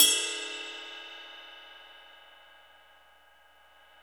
CYM XRIDE 5G.wav